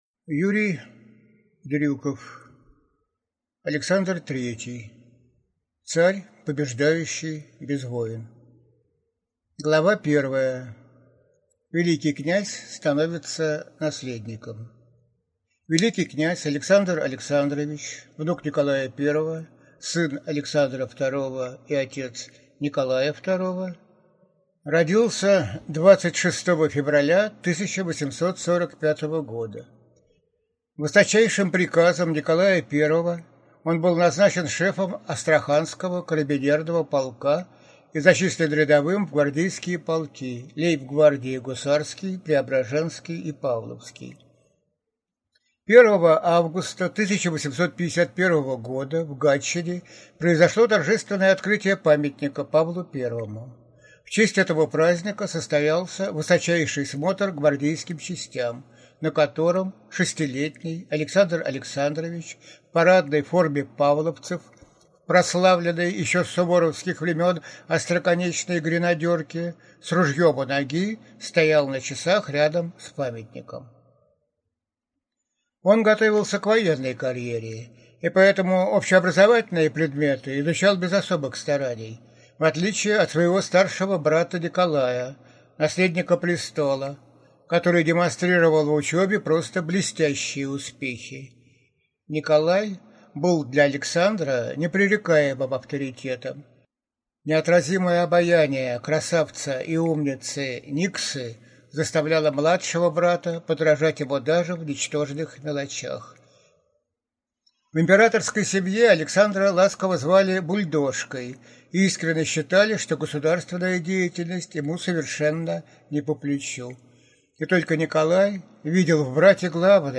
Аудиокнига Александр III. Царь, побеждающий без войн | Библиотека аудиокниг